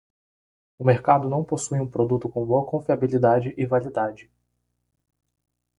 Pronounced as (IPA) /va.liˈda.d͡ʒi/